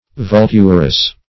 Vulturous \Vul"tur*ous\, a.